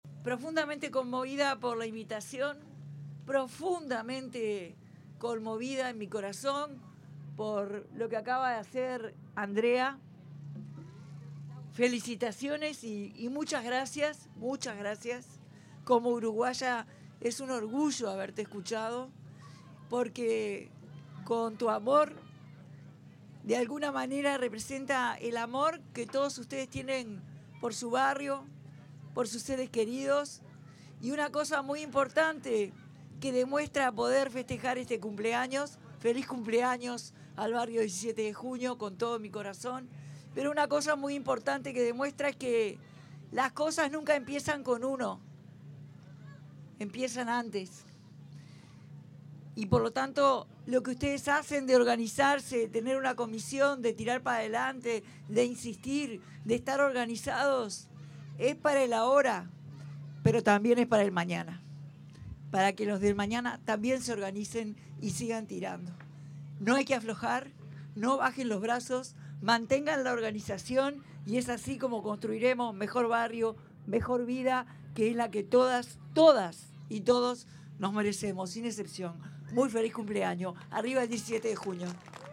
Durante la conmemoración del 30.° aniversario de la comisión barrial 17 de Junio, en Montevideo, se expresó la presidenta de la República en ejercicio